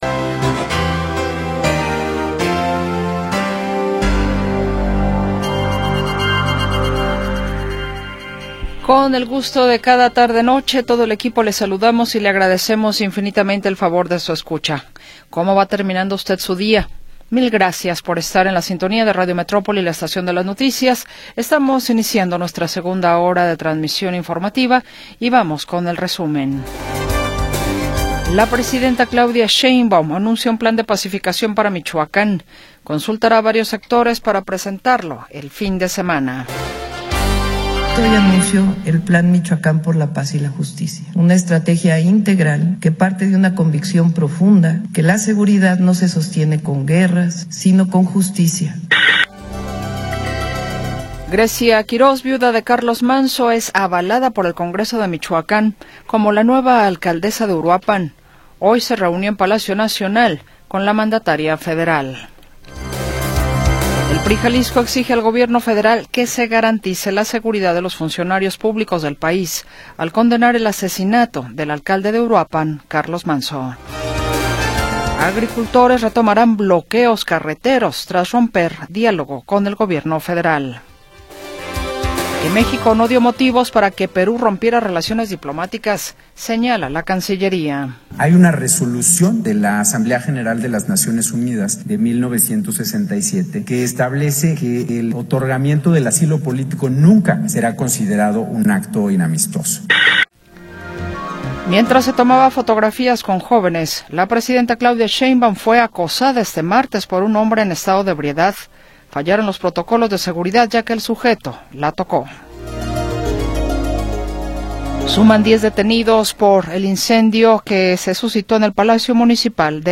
Segunda hora del programa transmitido el 4 de Noviembre de 2025.